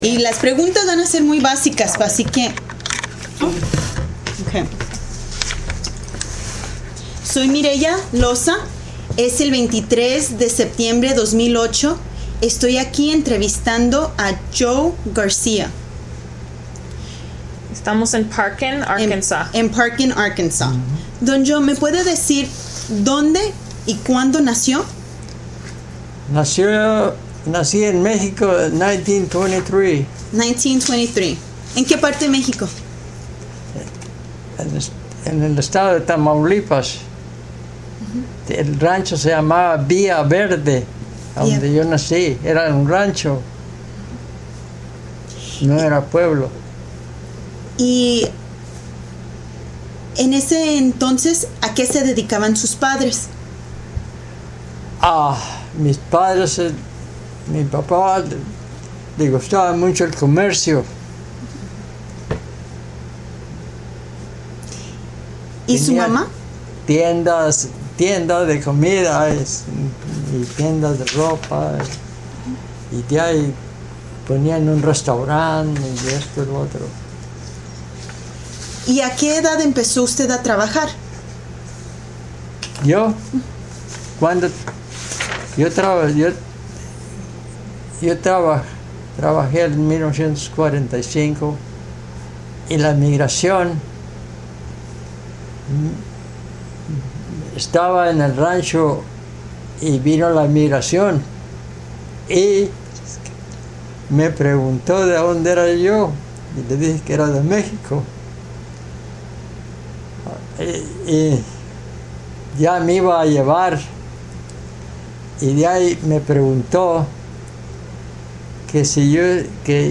Location Parkin, Arkansas